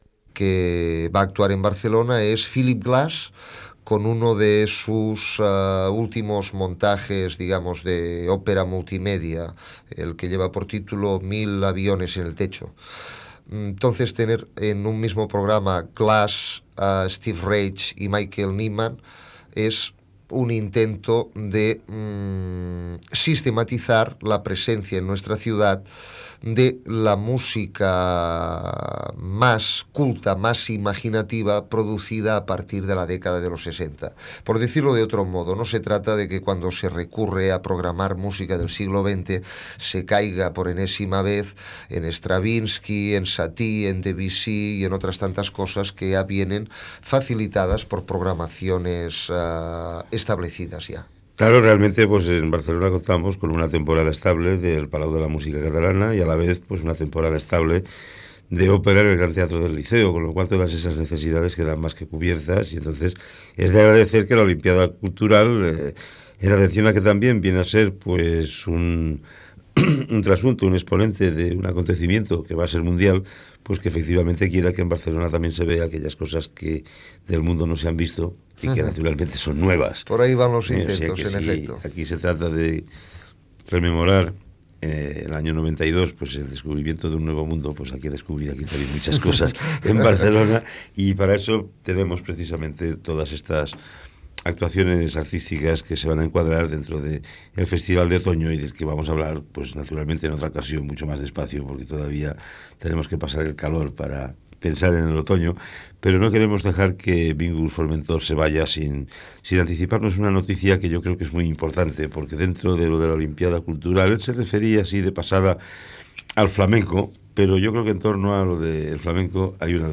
Entreteniment
Banda